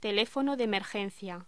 Locución: Teléfono de emergencia
voz